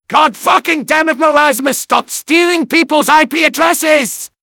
medic_jeers08.mp3